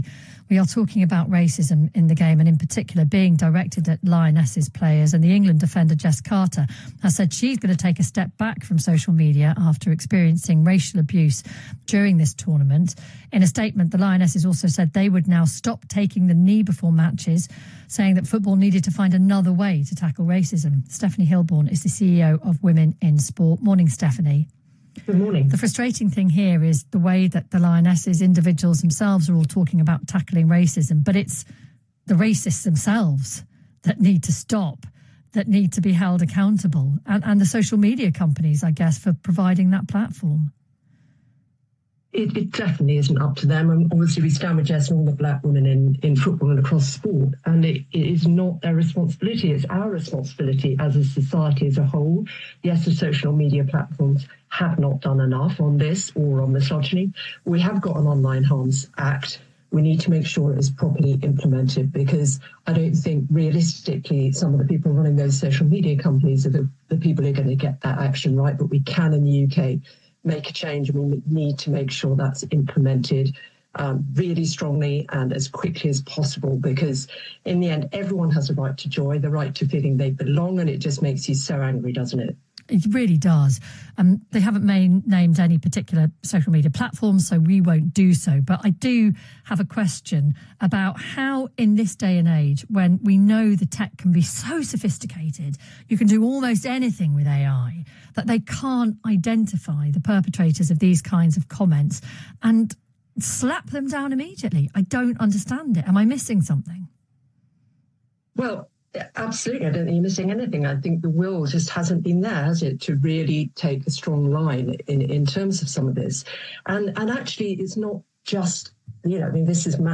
speaking on BBC Radio 5 Live